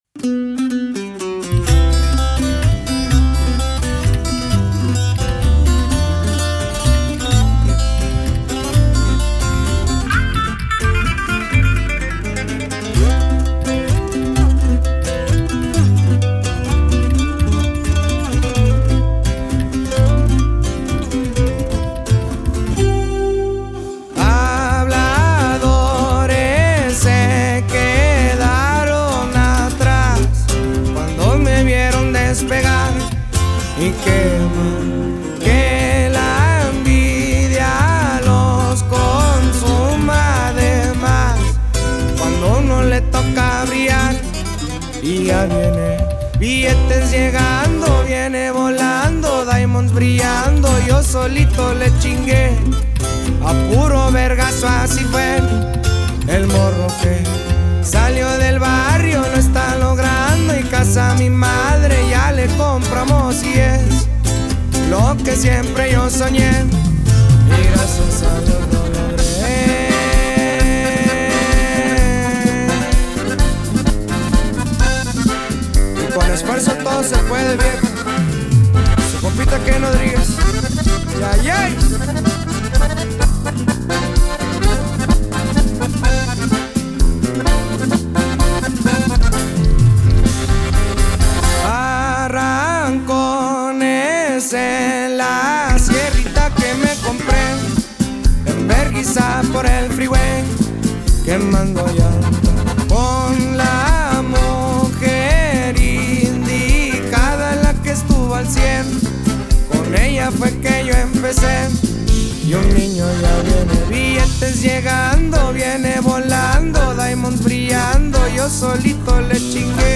Трек размещён в разделе Зарубежная музыка / Поп.